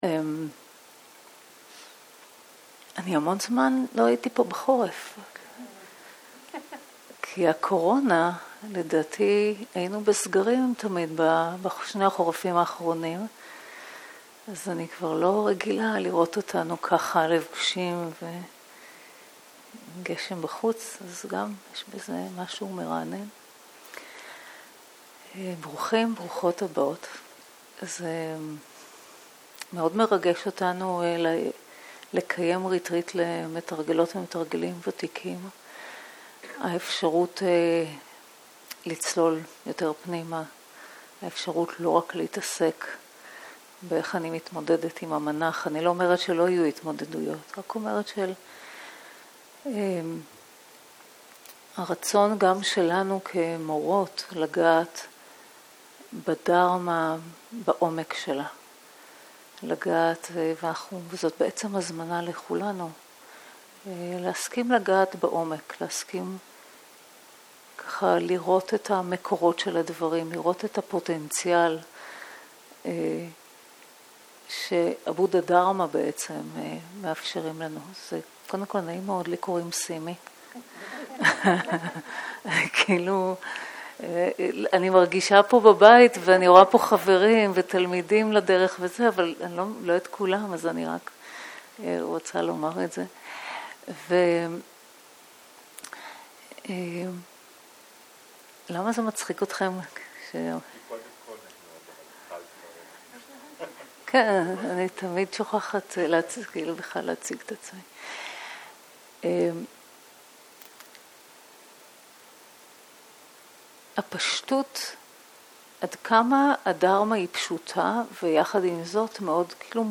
Dharma type: Opening talk